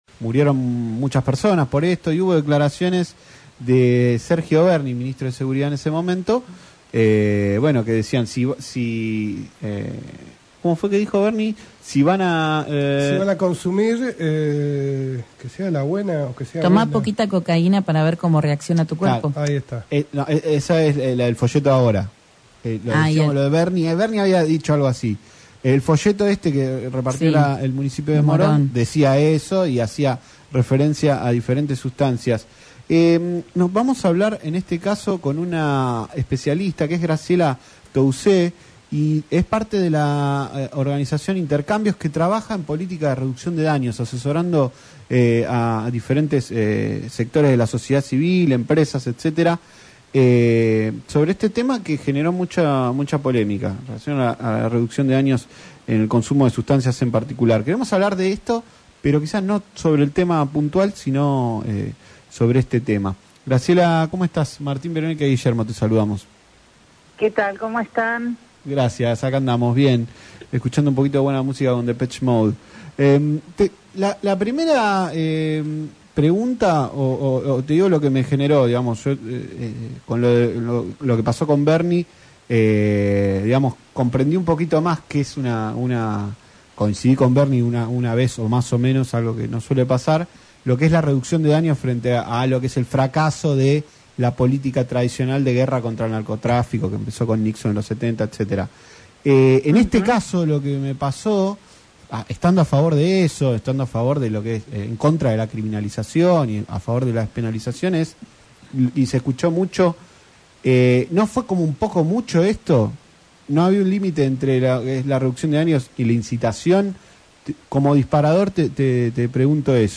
especialista en reducción de daños, habló en Tarde Para Miles por LaCienPuntoUno se refirió al folleto que difundió la Municipalidad de Morón sobre el consumo de drogas.